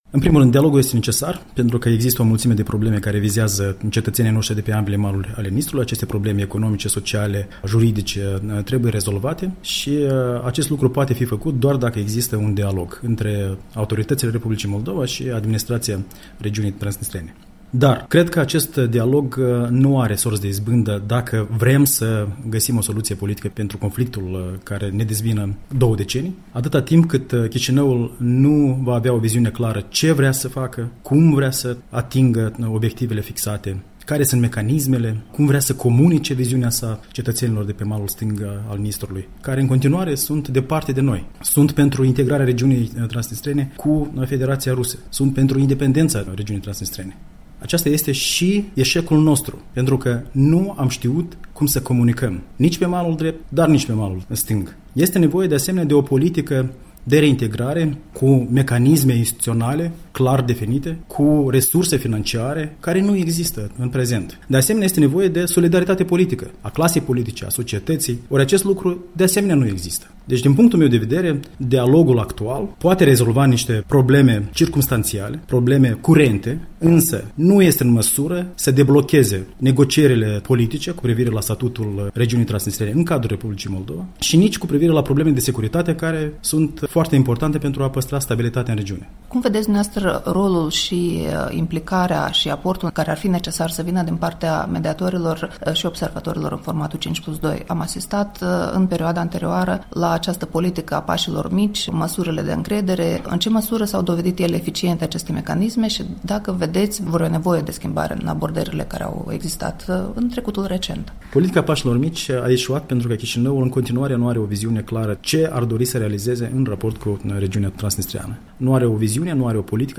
Un interviu în exclusivitate cu